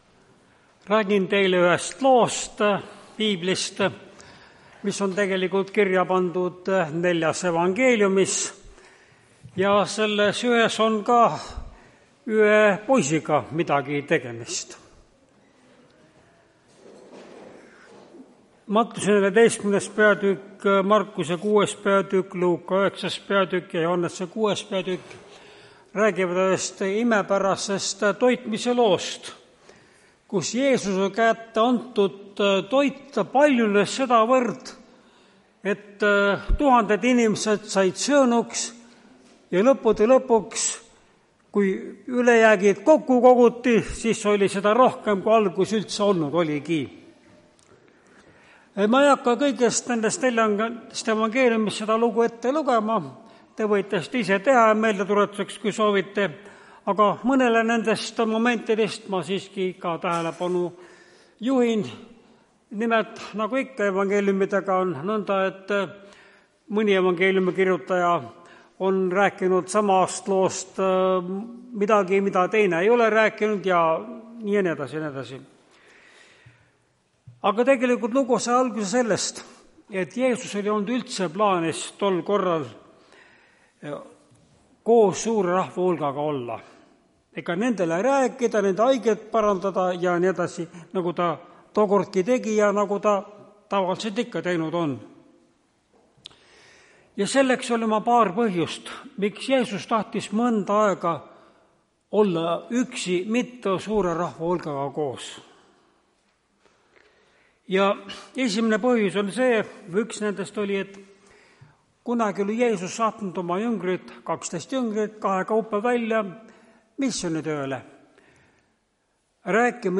Tartu adventkoguduse 17.05.2025 hommikuse teenistuse jutluse helisalvestis.
Jutlused